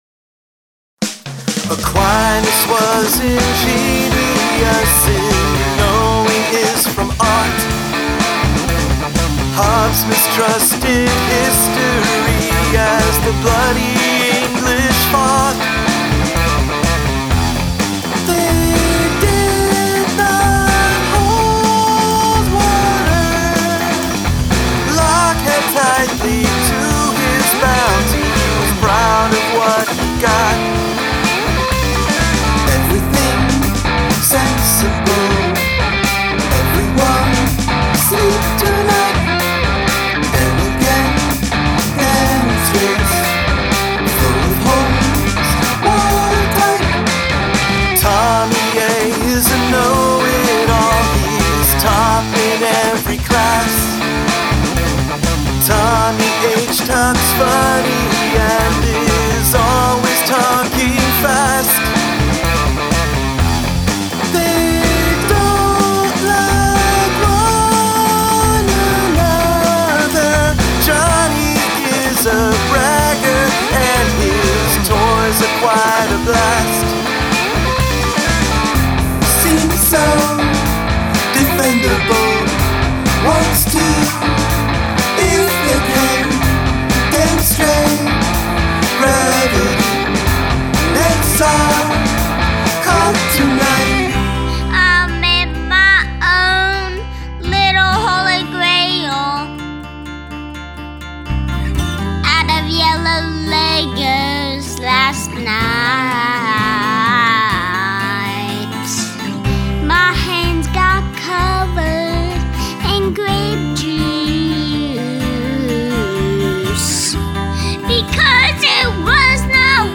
- bridge vocals
- guitar solo and end guitarmonies